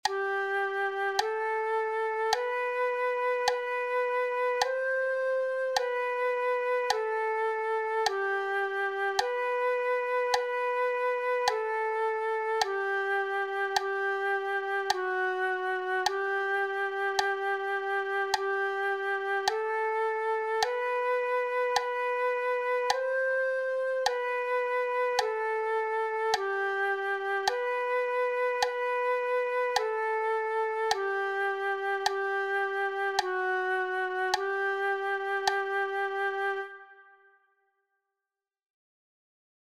Branle_A_Lento.mp3